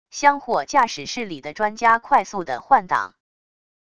箱货驾驶室里的专家快速的换挡wav音频